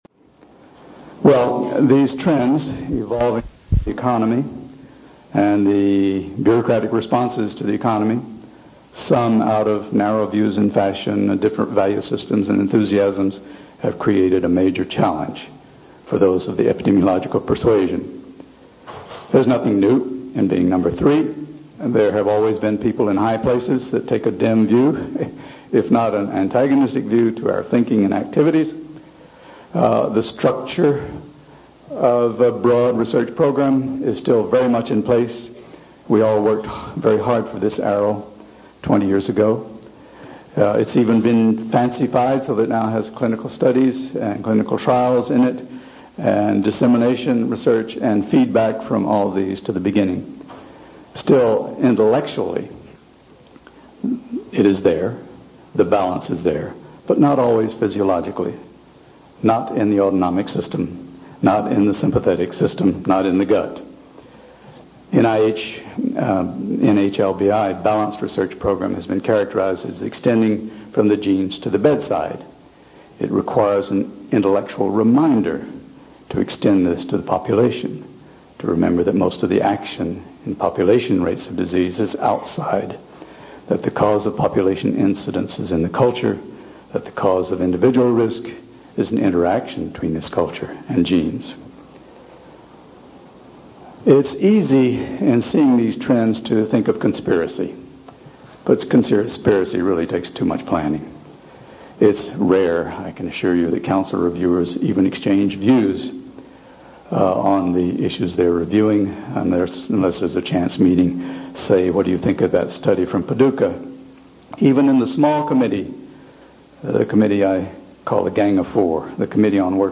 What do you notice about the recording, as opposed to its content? These audio segments are extracts from the Ancel Keys Lecture at the American Heart Association Meeting in Anaheim in 1991, a crossroads period in CVD epidemiology during which the built-in balance of NHLBI programs was heavily affected by funding and policy changes.